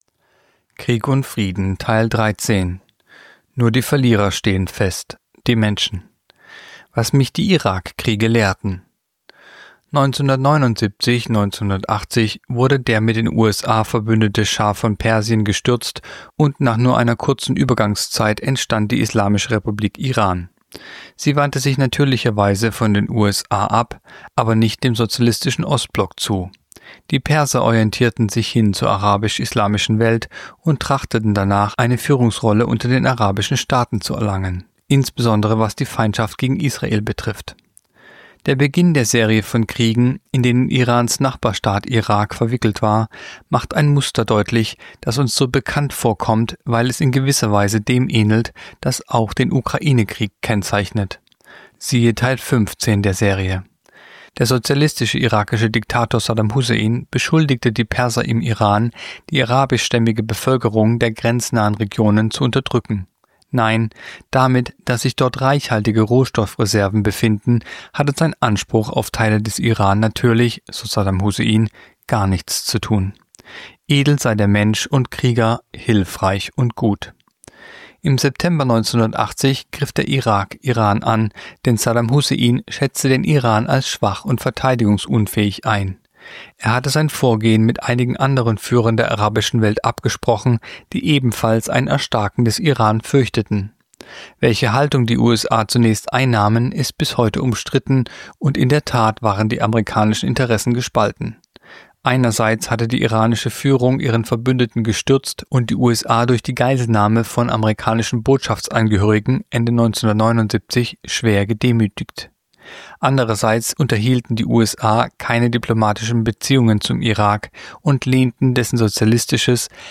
Kolumne der Woche (Radio)Nur die Verlierer stehen fest: Die Menschen